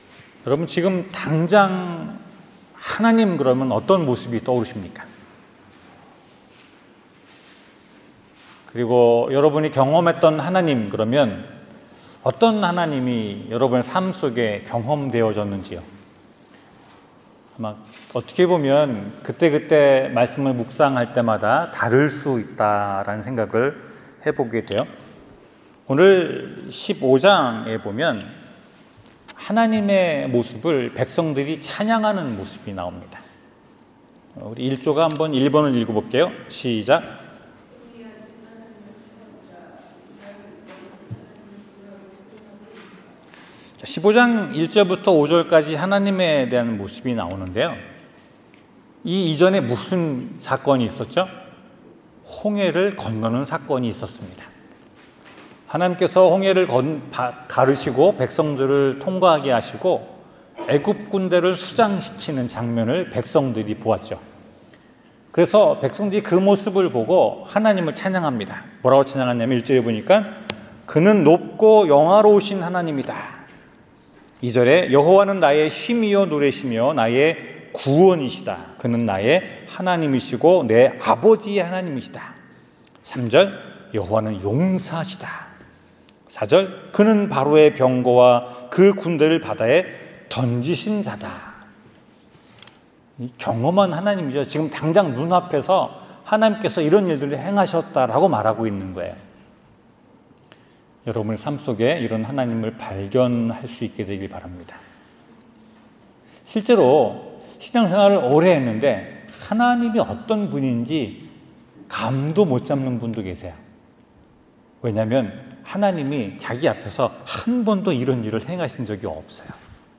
17:2절 설교